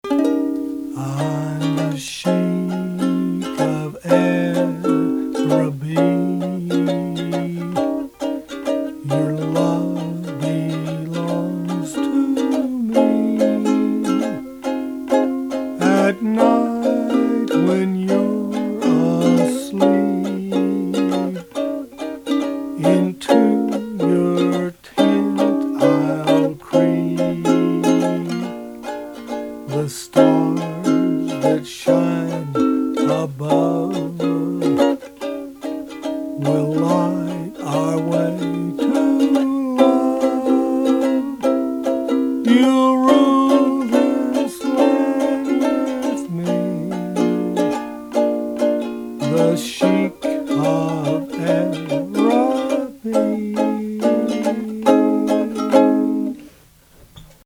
Ukulele mp3 songs from sheet music
Please ignore any sour notes.